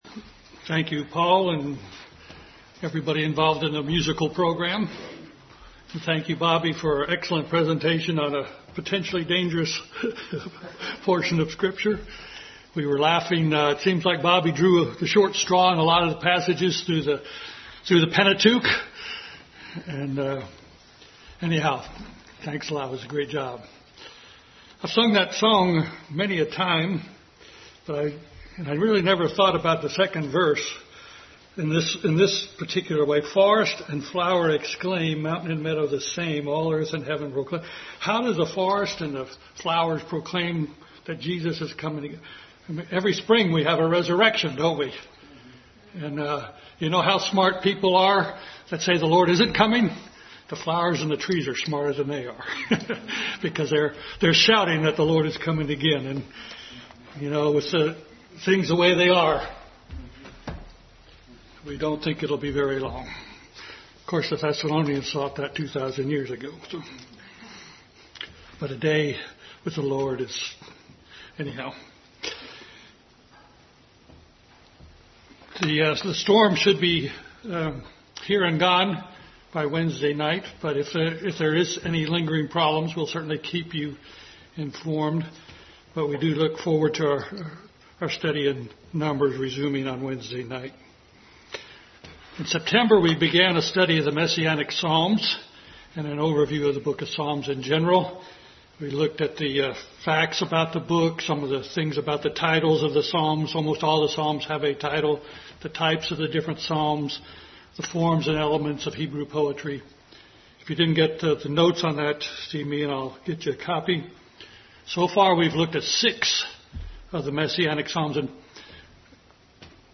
Bible Text: Psalm 40, Genesis 50:25-26, Psalm 91, Psalm 18, Psalm 22, Hebrews 10:5-7, Isaiah 50:5, 1 Peter 1:18-20 | Family Bible Hour Message.